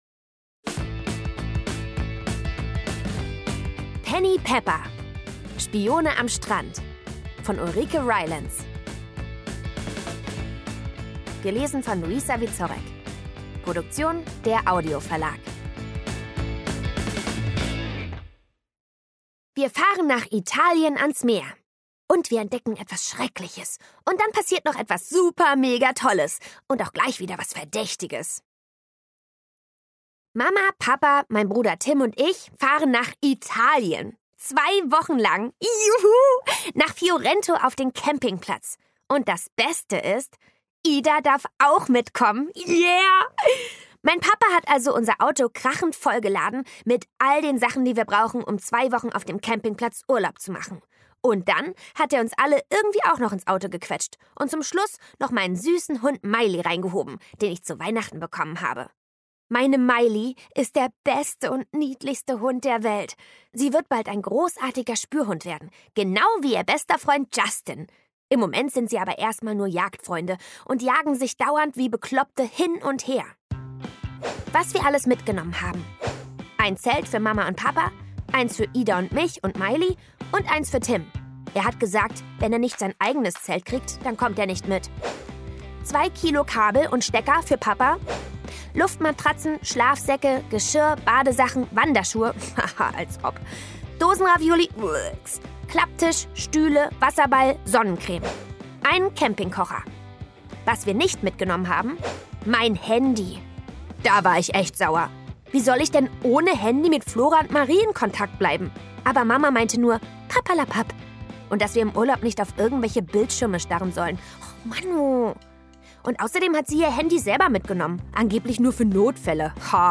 Penny Pepper – Teil 5: Spione am Strand Szenische Lesung mit Musik